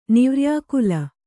♪ nirvyākula